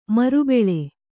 ♪ maru beḷe